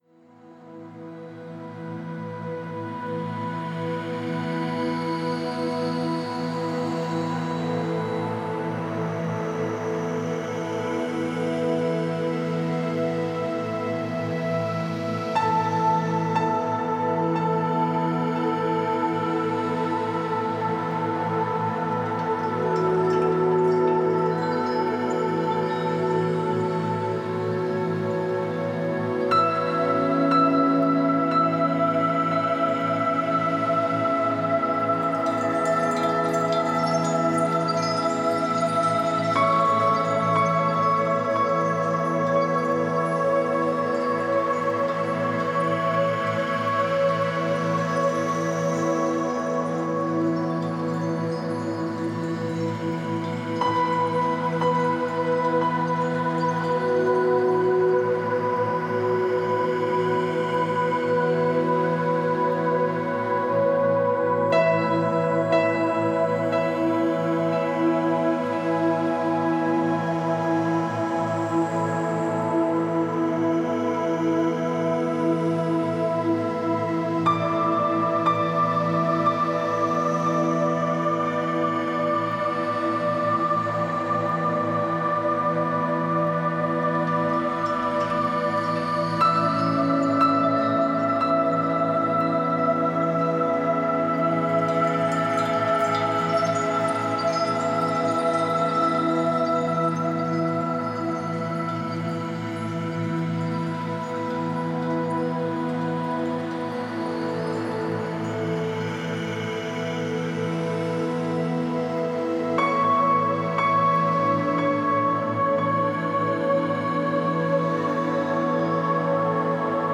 Genre: Meditation